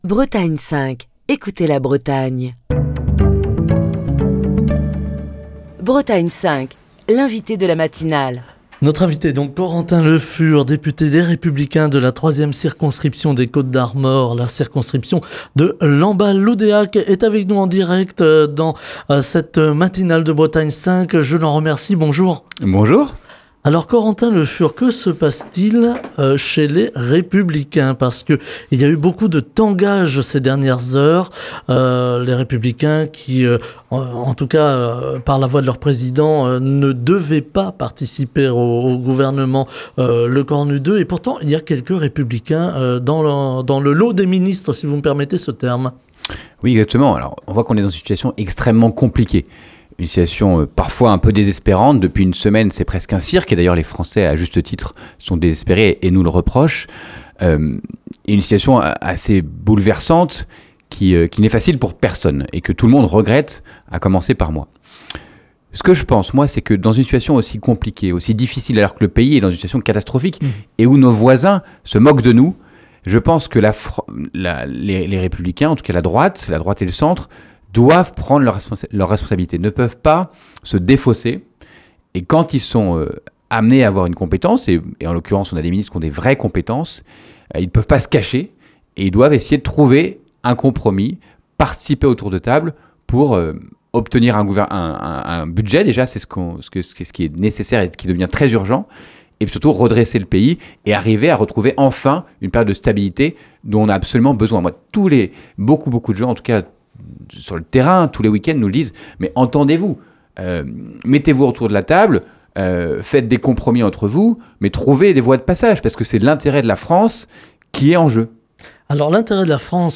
Corentin Le Fur, député Les Républicains de la 3e circonscription des Côtes d’Armor (Lamballe - Loudéac) était l'invité politique de la matinale de Bretagne 5.